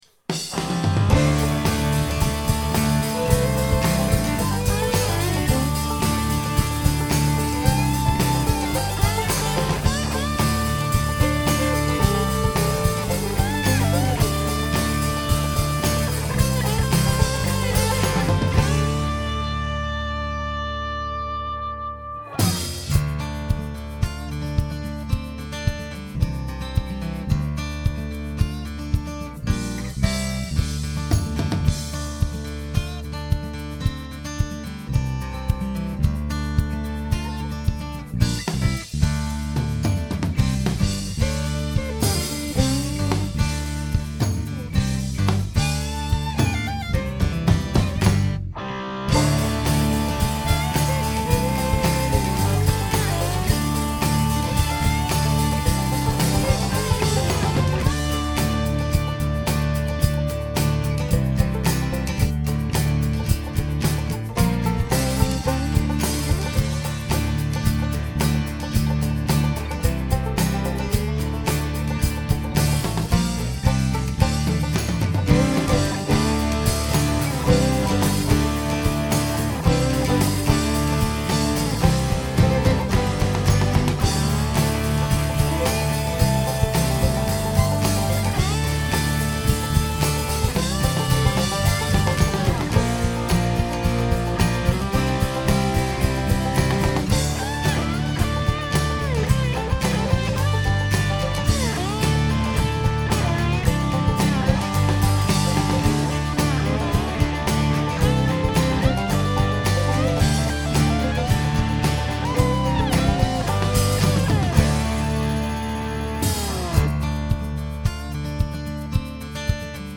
no vocals, unmixed raw tracks